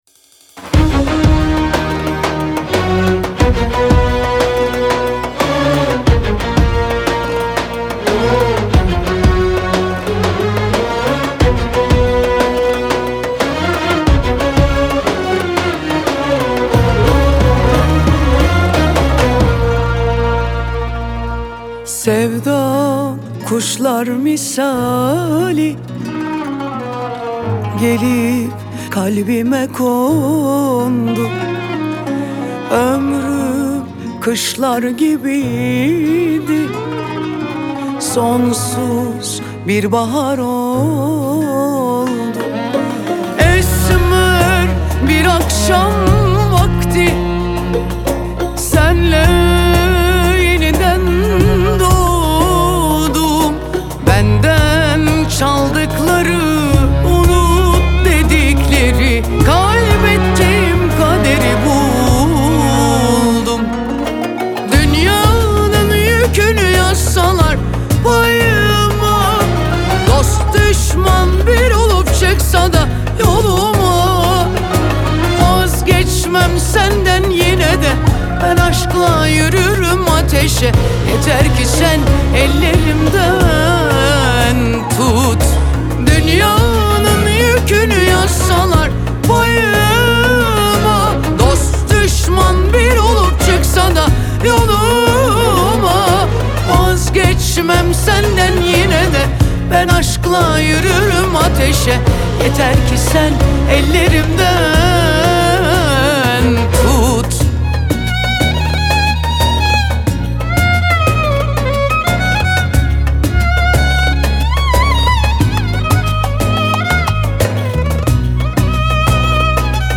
آهنگ ترکیه ای آهنگ غمگین ترکیه ای آهنگ هیت ترکیه ای